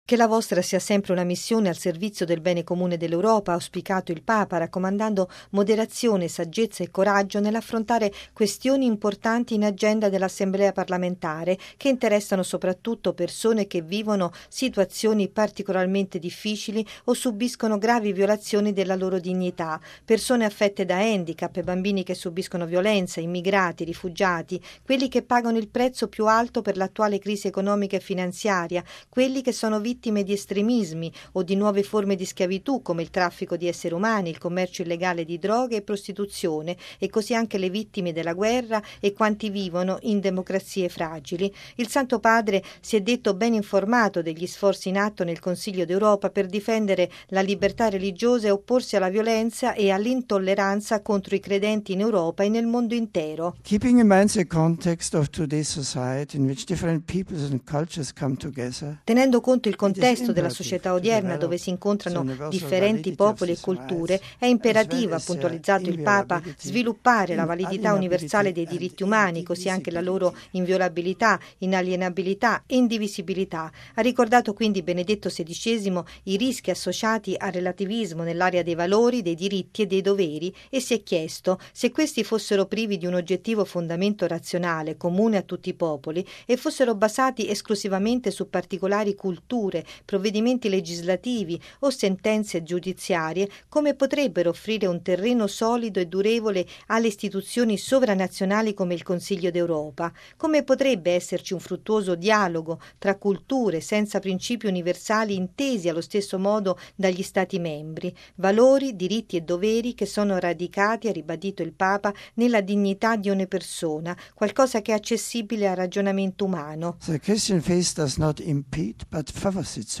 ◊   “Moderazione, saggezza e coraggio” sostengano la vostra “missione delicata e importante”, così Benedetto XVI, ricevendo stamane i membri dell’Ufficio dell’Assemblea parlamentare del Consiglio d’Europa. L’incontro è avvenuto nell’Auletta Paolo VI, dopo l’udienza generale, in occasione del 60.mo anniversario della Convenzione europea dei diritti dell’uomo.